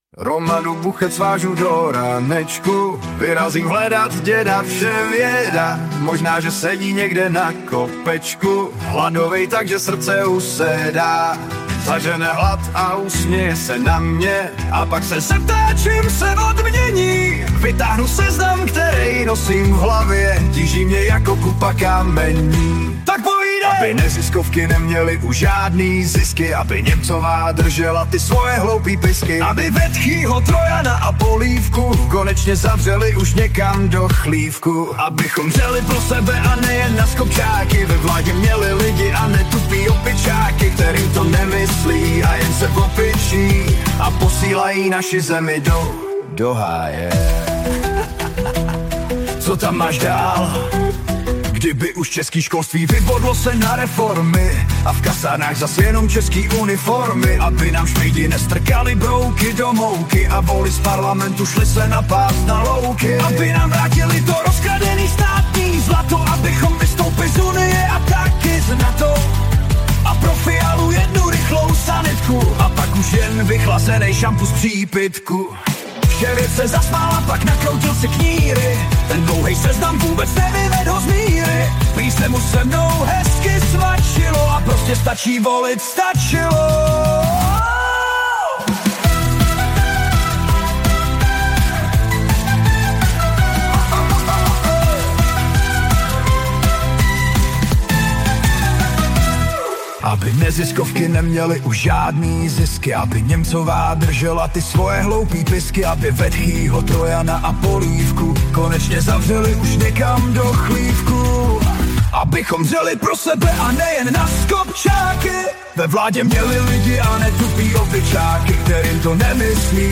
2025-10-02 – Studio Kladno Svobodného rádia: Předvolební rozhovor s europoslankyní Kateřinou Konečnou na aktuální témata a proč jít volit.